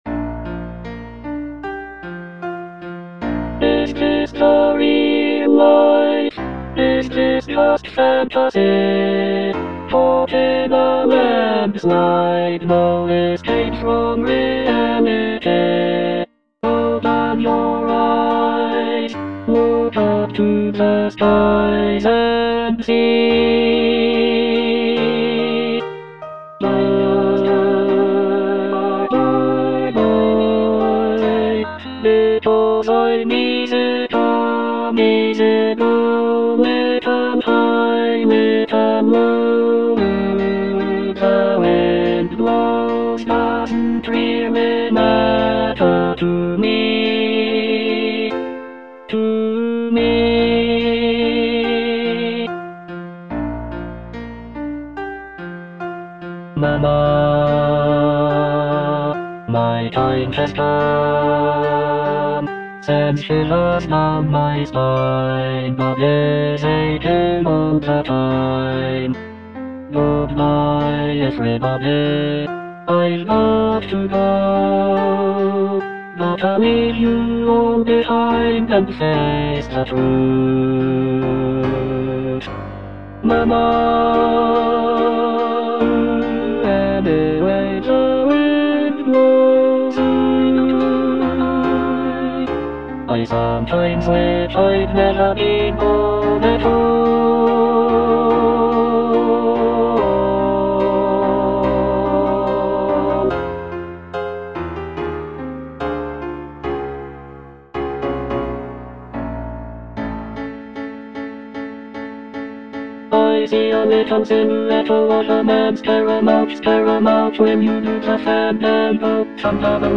Bass I (Emphasised voice and other voices)